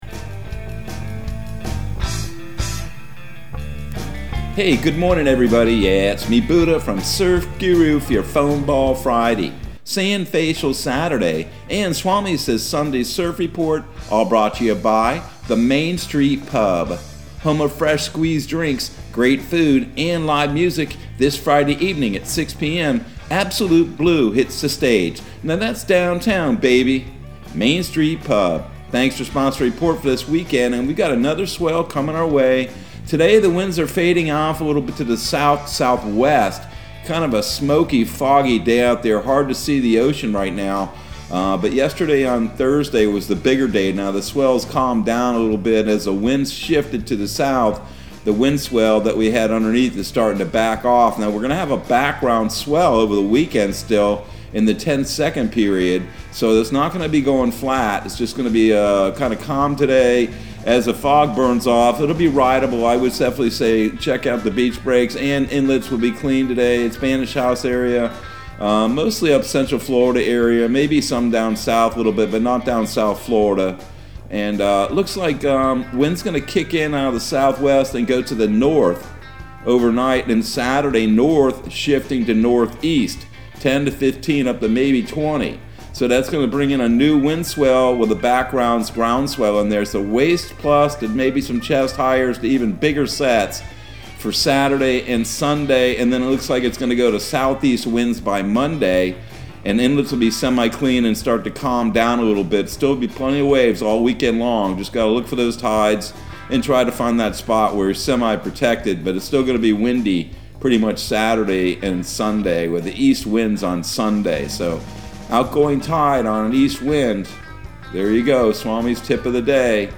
Surf Guru Surf Report and Forecast 02/18/2022 Audio surf report and surf forecast on February 18 for Central Florida and the Southeast.